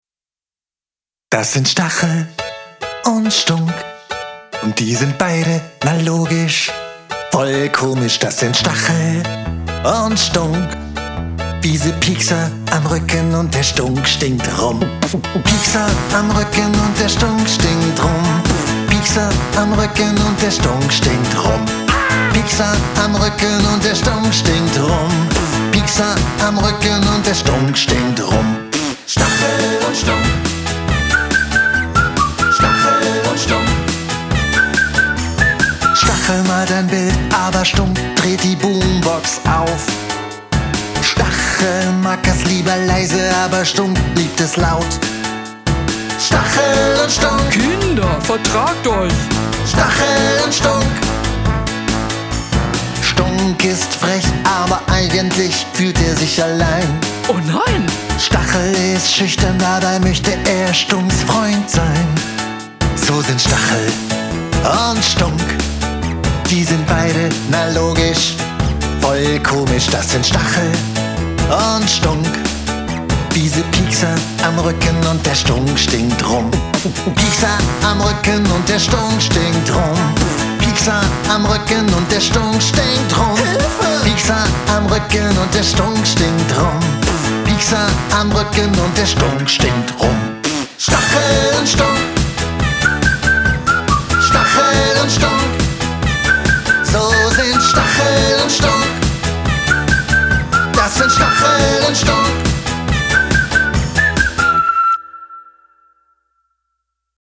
Sing mit!